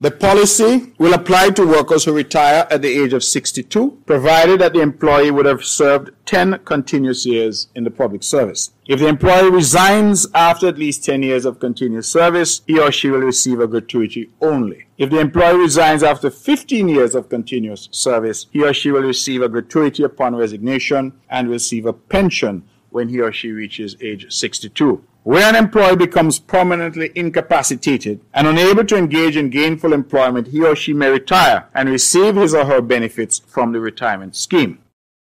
Mr. Brantley further explained the policy: